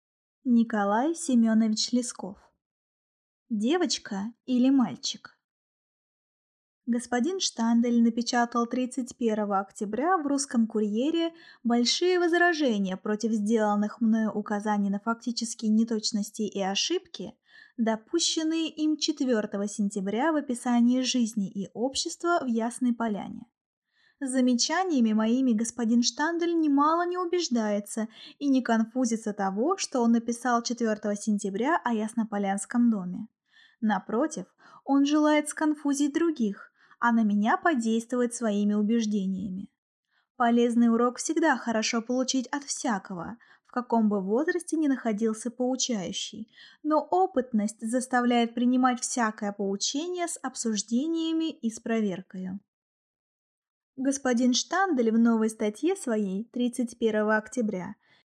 Аудиокнига Девочка или мальчик?